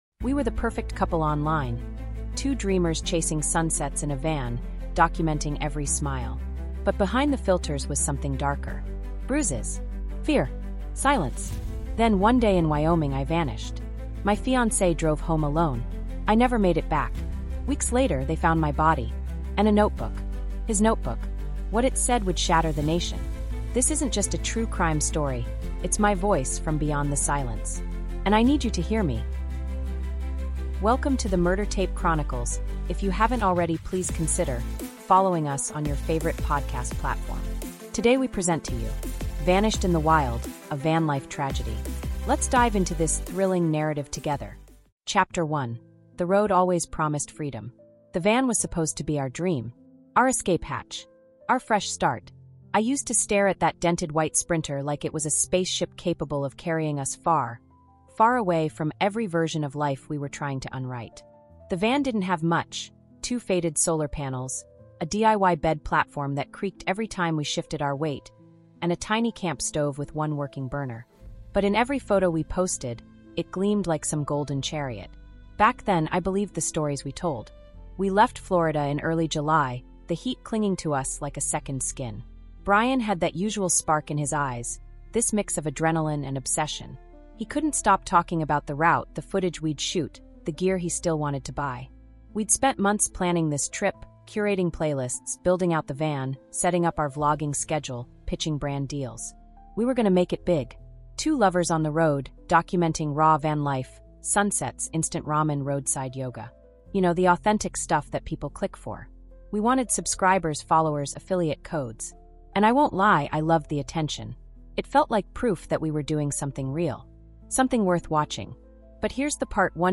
In Vanished in the Wild: A Van-Life Tragedy, journey deep into the haunting true story of a 22-year-old travel vlogger whose cross-country adventure with her fiancé ended in unthinkable tragedy. Told from the raw, emotionally gripping first-person perspective of the victim herself, this five-chapter audiobook exposes the hidden horror of intimate partner violence behind the curated perfection of social media.